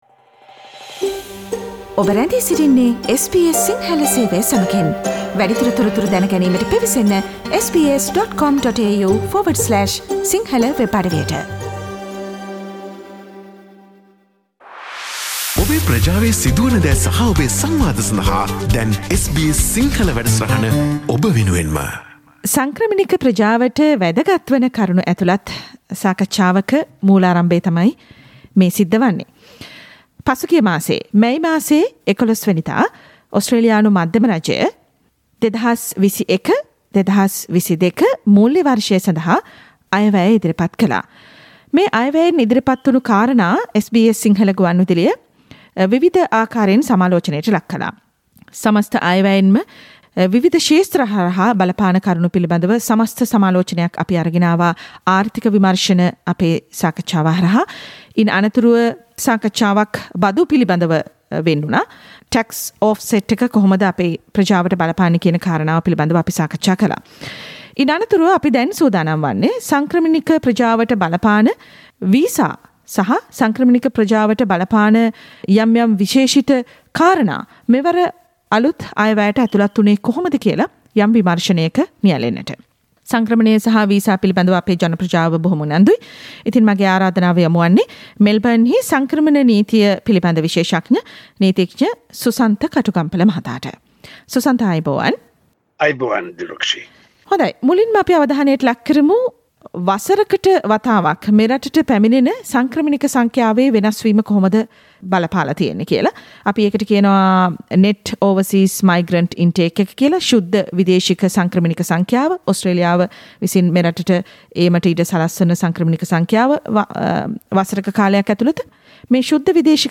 ඕස්ට්‍රේලියානු රජය 2021 - 2022 මුල්‍ය වර්ෂය සඳහා මැයි මාසයේදී ඉදිරිපත්කල මධ්‍යම රජයේ අයවැයෙන් මෙම නව මුල්‍ය වර්ෂය සඳහා සංක්‍රමණිකයින් සහ වීසා සම්බන්දව සහ වාර්ෂික සංක්‍රමණික කෝටාව පිළිබඳව ඉදිරිපත් කරන ලද කරුණු පිළිබඳව වන සමාලෝචනයක් SBS සිංහල ගුවන්විදුලිය ගෙන එන මෙම සාකච්චාවෙන් ඔබට සවන්දිය හැකියි.